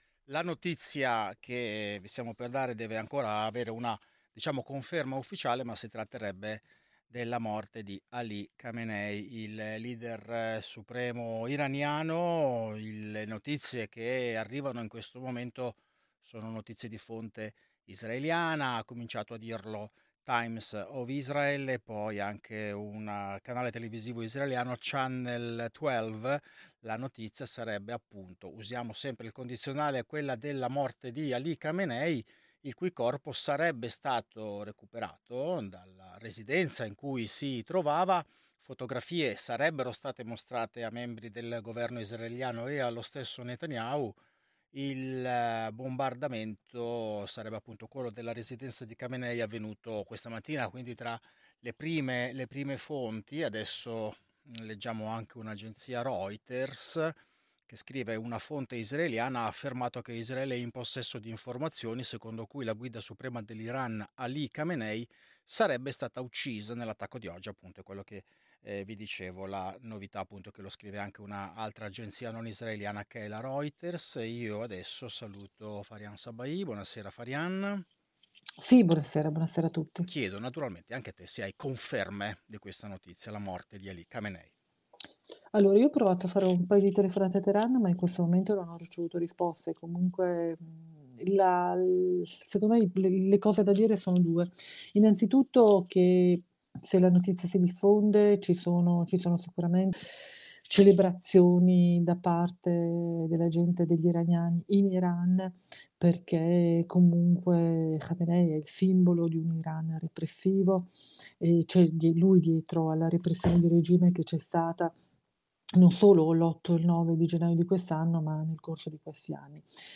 Ascolta lo speciale andato in onda dalle 21 alle 21.30, in studio